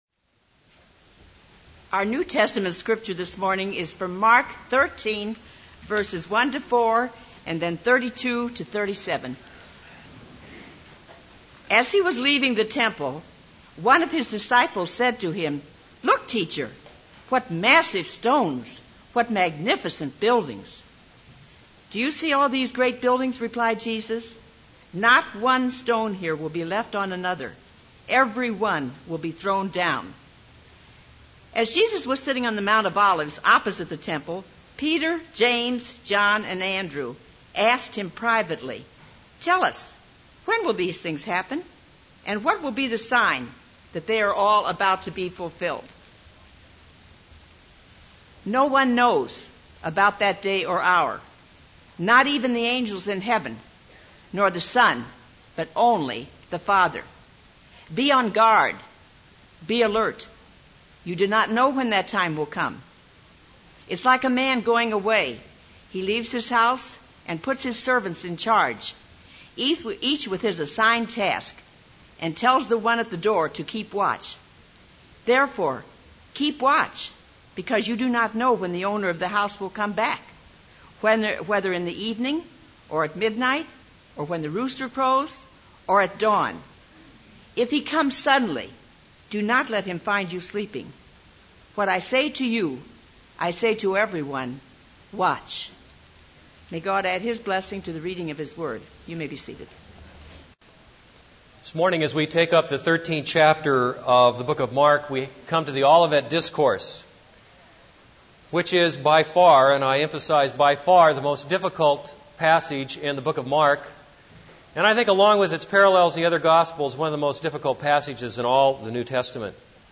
This is a sermon on Mark 13:1-4 & Mark 13:32-37.